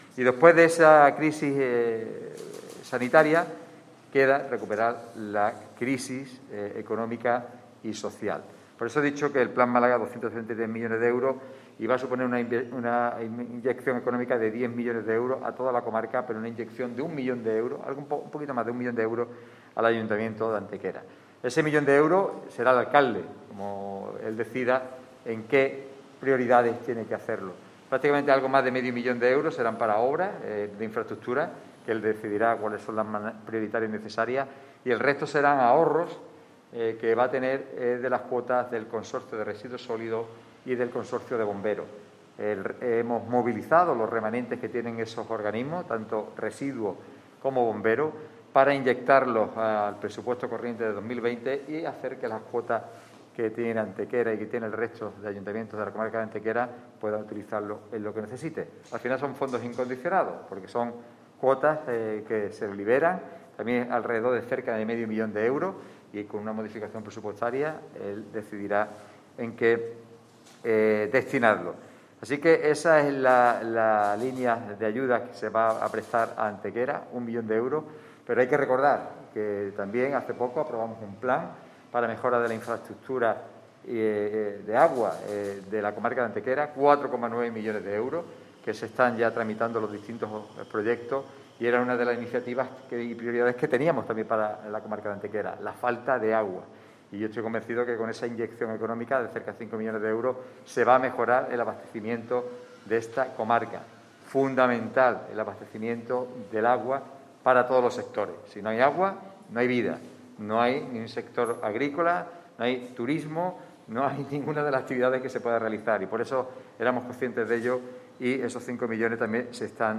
Así lo ha anunciado este mediodía en el Ayuntamiento de Antequera el presidente de la Diputación, Francisco Salado, en una rueda de prensa en la que ha estado acompañado por el alcalde de Antequera, Manolo Barón, así como por el concejal antequerano y a su vez diputado provincial Juan Álvarez.
Cortes de voz